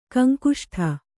♪ kaŋkuṣṭa